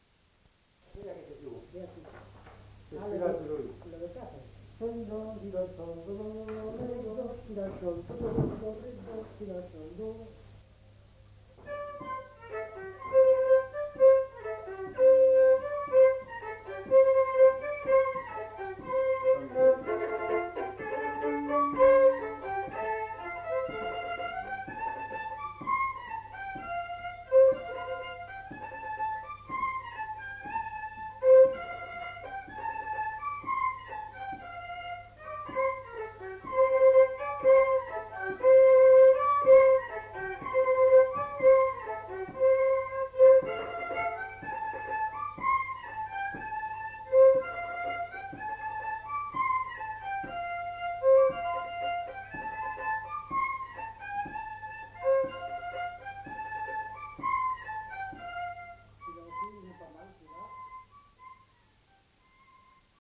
Rondeau
Aire culturelle : Lomagne
Genre : morceau instrumental
Instrument de musique : violon
Danse : rondeau